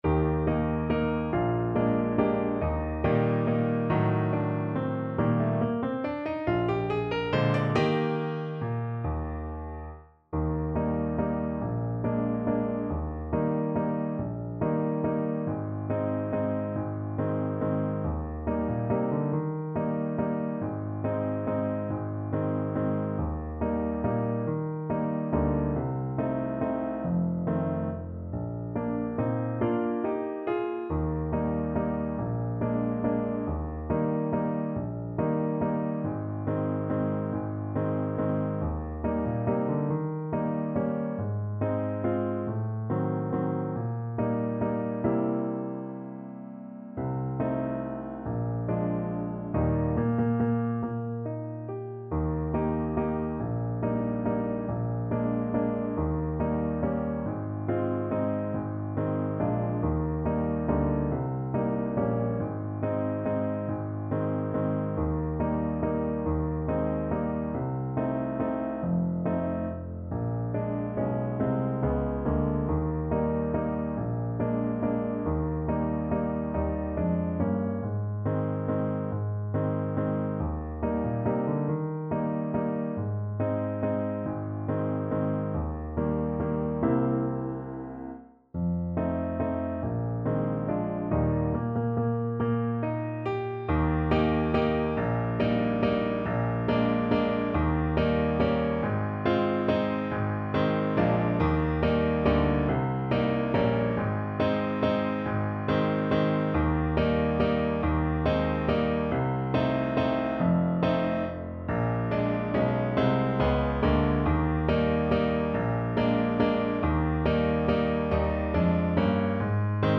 3/4 (View more 3/4 Music)
~ = 140 Tempo di Valse